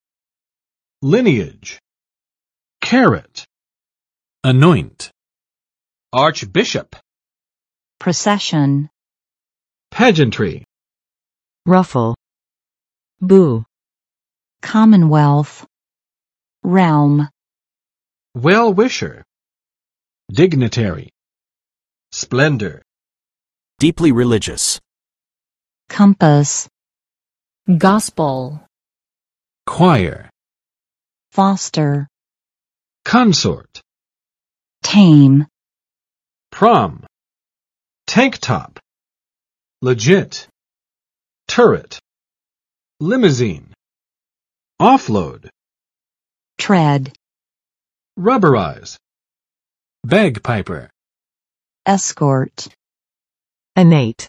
[ˋlɪniɪdʒ] n. 后裔；家系，世系